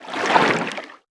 File:Sfx creature titanholefish swim 03.ogg - Subnautica Wiki
Sfx_creature_titanholefish_swim_03.ogg